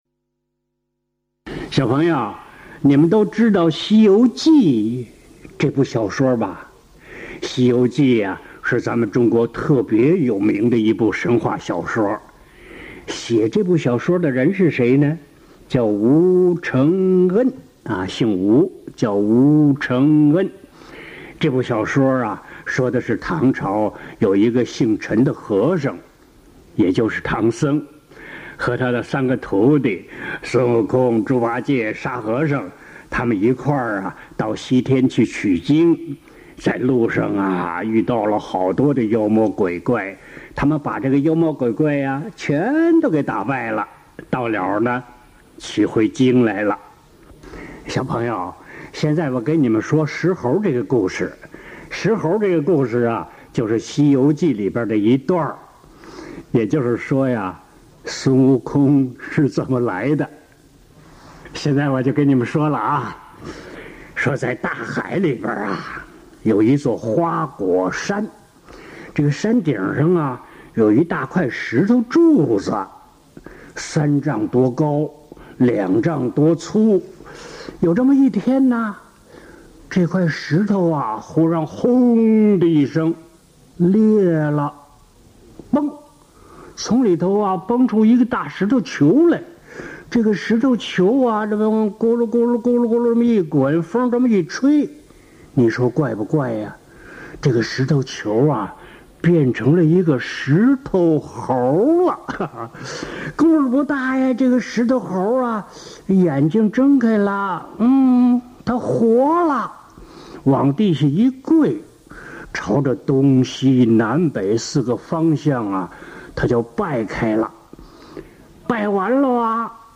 儿童有声故事：孙敬修爷爷讲西游记（28集）mp3下载